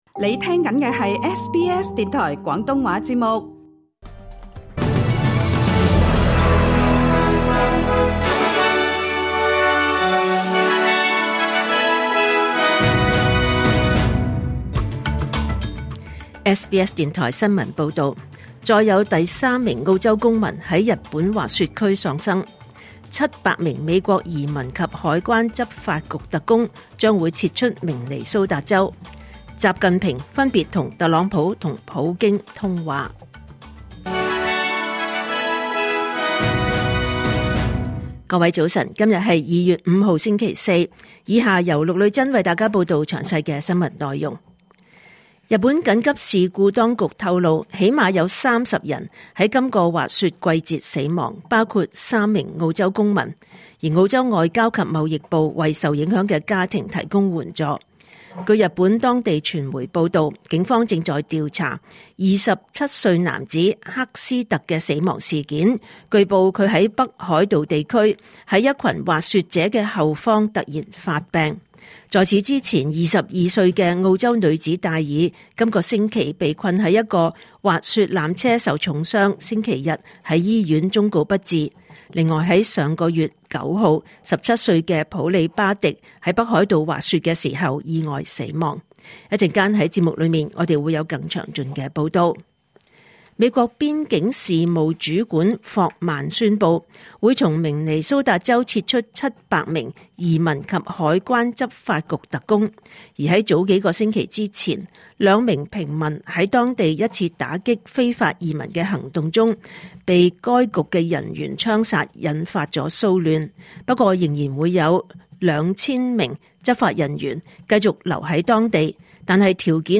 2026年 2 月 5 日SBS廣東話節目九點半新聞報道。